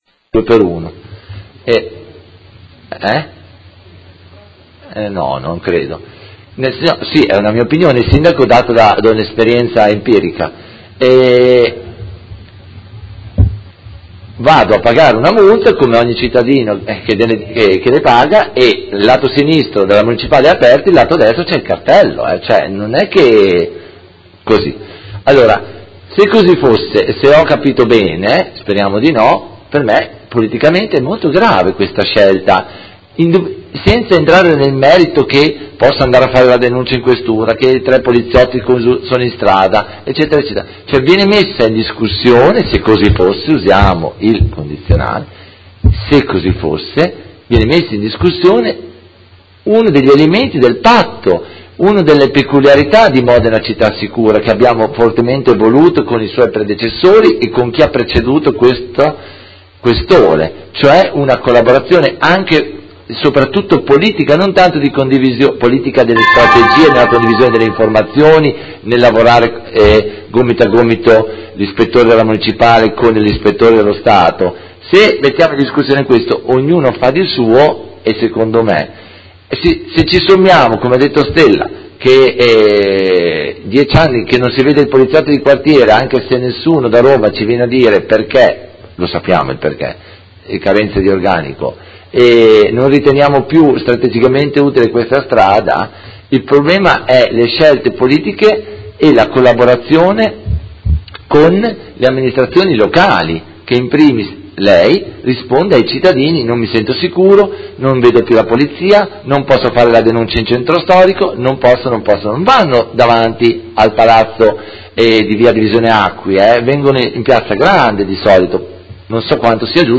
Seduta del 19/10/2017 Replica a risposta Sindaco. Interrogazione del Consigliere Carpentieri (PD) avente per oggetto: Sicurezza in città con particolare riferimento alla zona di Viale Gramsci